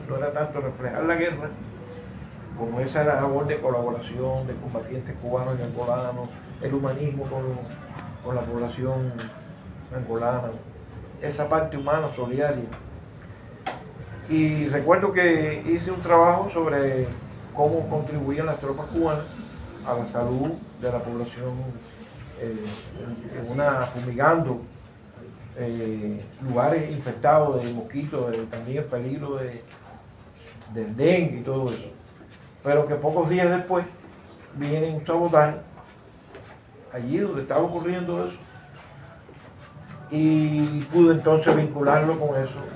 El encuentro no sumó años, más bien sapiencia e historia contenida en la memoria y el alma de jubilados de diferentes medios de comunicación, quienes fueron convocados por la Unión de Periodistas de Cuba (Upec) en la Isla de la Juventud, en ocasión de la Jornada de la Prensa Cubana.